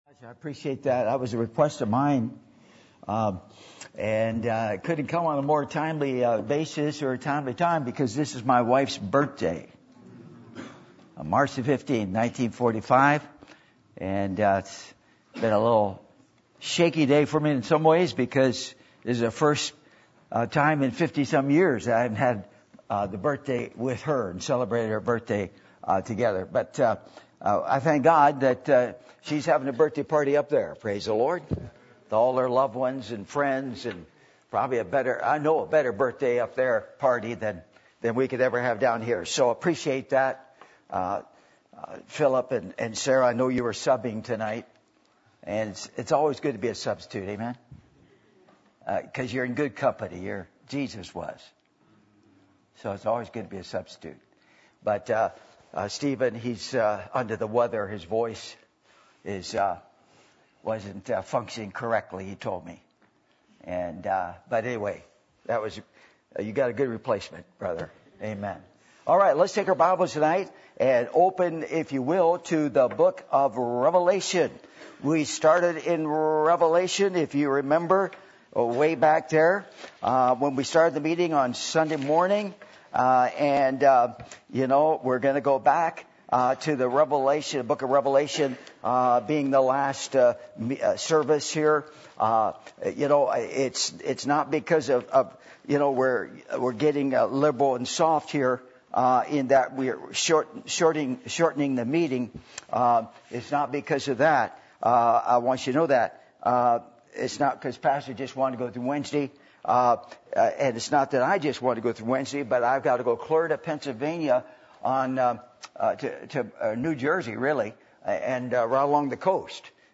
Passage: Revelation 19:11-20 Service Type: Revival Meetings %todo_render% « A Professor Or A Possessor?